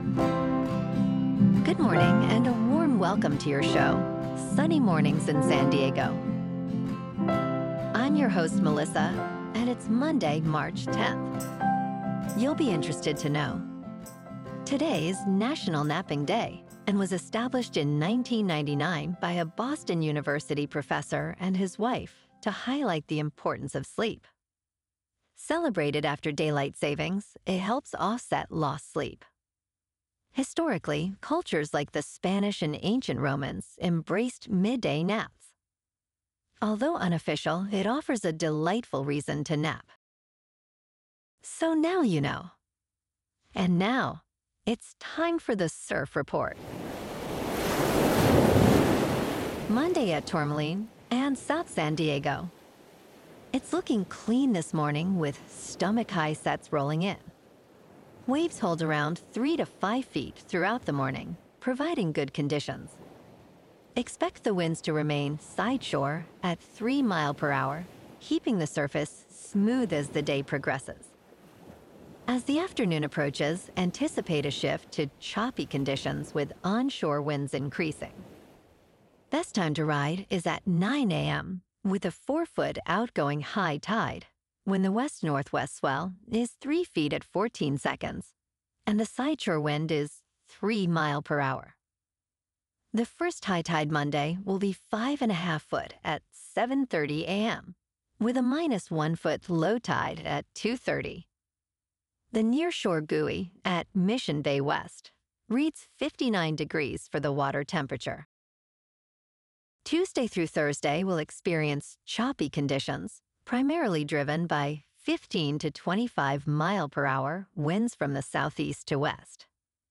Your "Hyper-Local" 12 Minute Daily Newscast with: